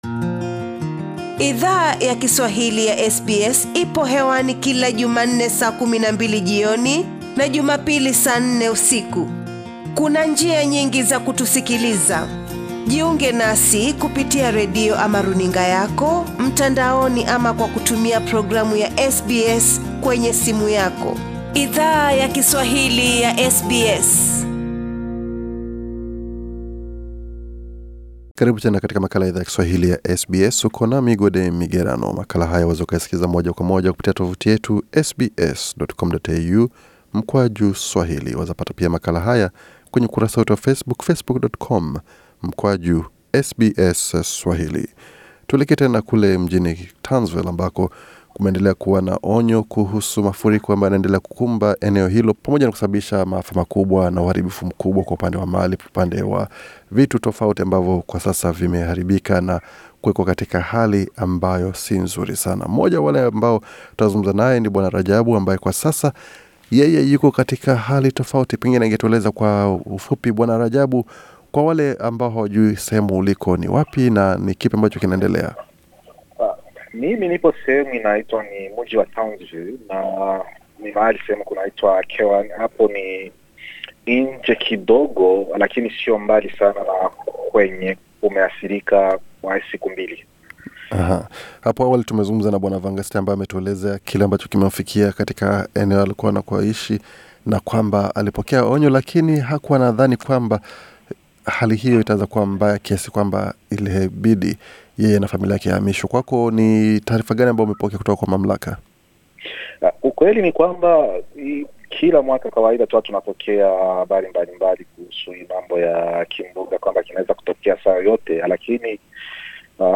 Wakazi wa maeneo ambayo yame athiriwa kwa mafuriko mjini Townsville wanapo endelea kuhesabu gharama ya uharibifu kwa nyumba na biashara zao, SBS Swahili ilizungumza na wanachama wa jamii wanao zungumza Kiswahili, ambao wana ishi katika vitongoji jirani vya maeneo ambayo yame athiriwa kwa mafuriko hayo.